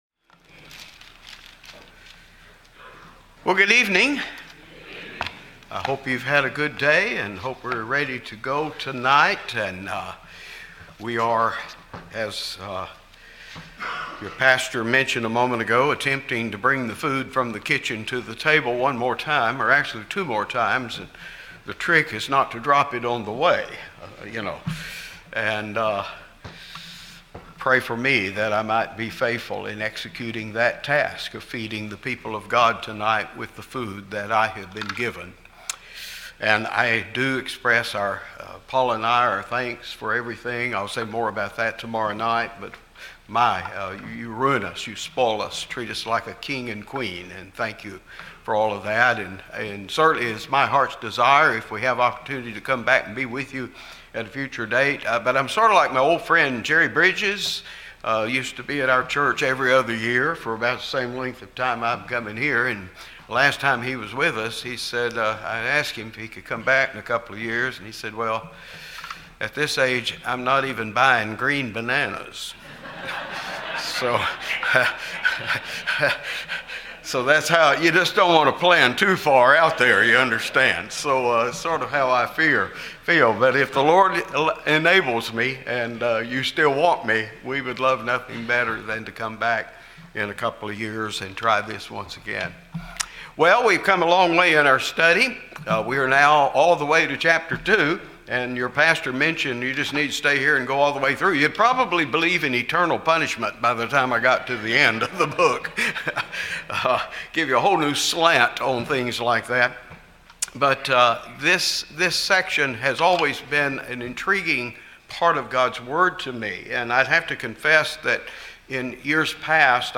This is the fourth of five messages in the 2025 Spring Bible Conference.